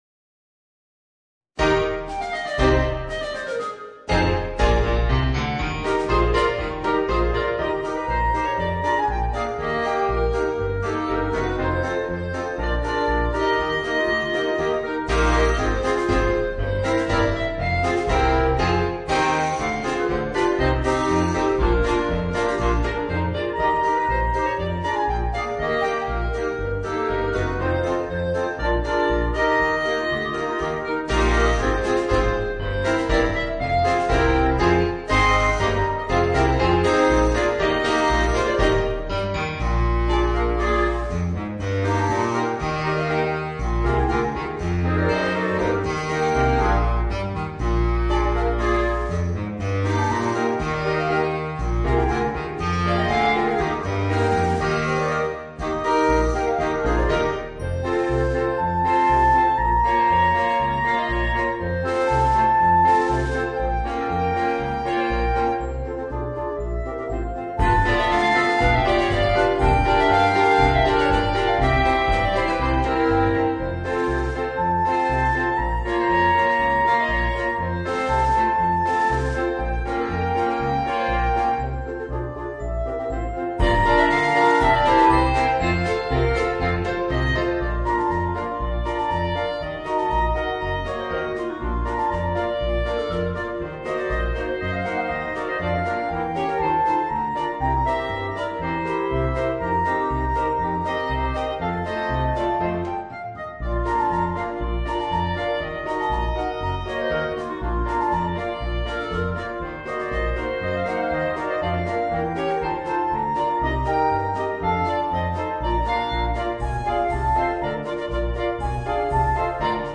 Voicing: 5 Clarinets